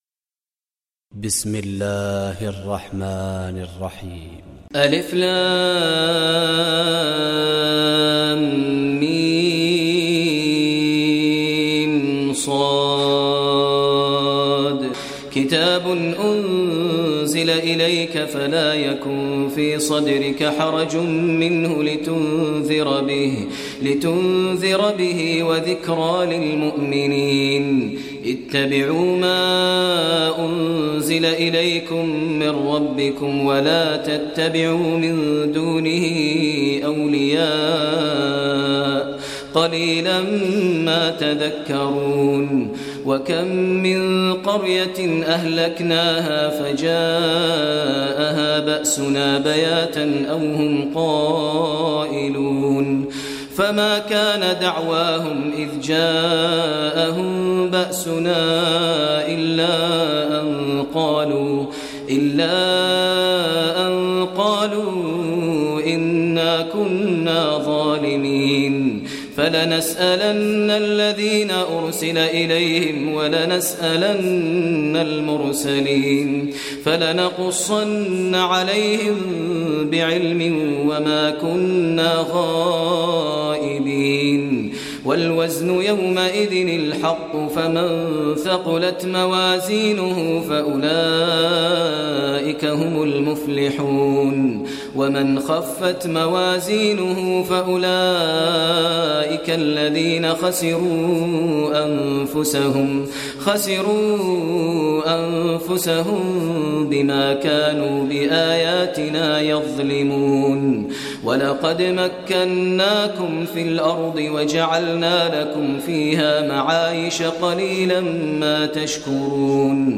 Surah Araf Recitation by Sheikh Maher Mueaqly
Surah Araf, listen online mp3 tilawat / recitation in Arabic in the voice of Sheikh Maher al Mueaqly.